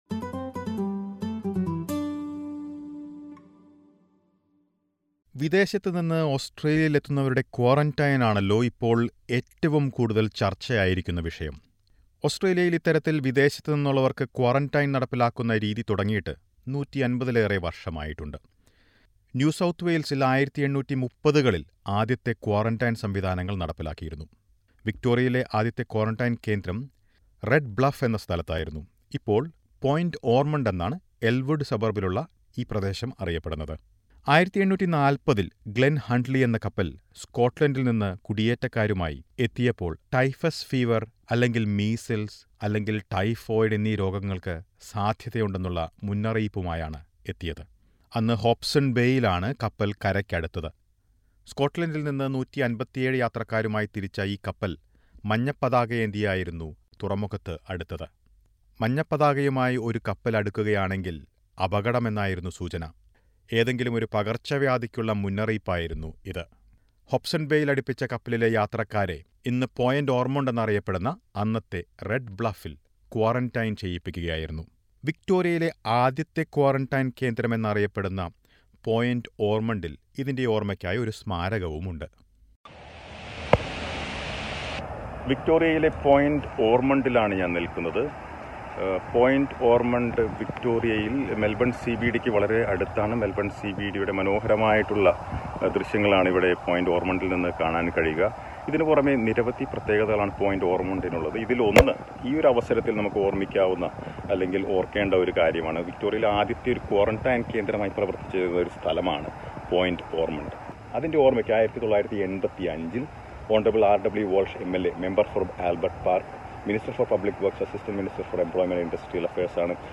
The first quarantine centre in Victoria dates back to 1840. One of the descendants of the ship Glen Huntly, that arrived in 1840 with a yellow flag alerting the possible danger of sick passengers on board, speaks to SBS Malayalam about the arrival of the ship in this report.